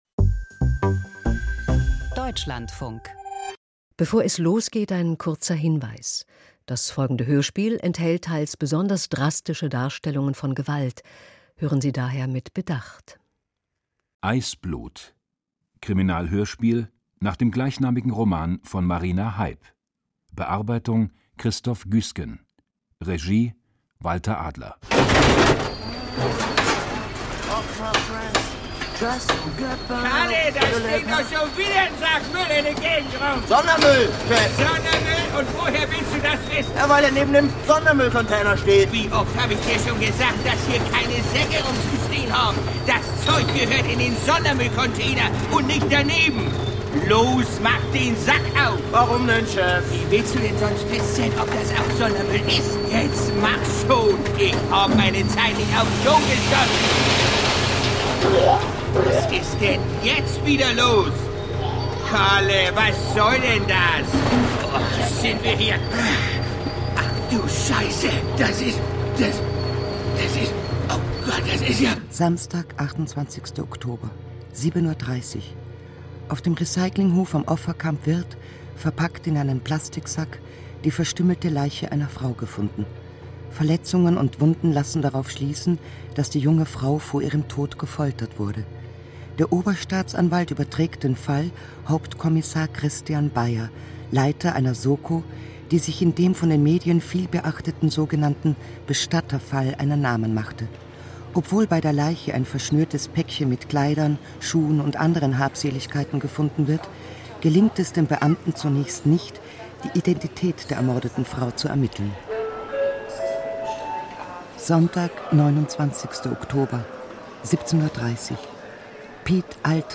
Eisblut – Krimi-Hörspiel von Marina Heib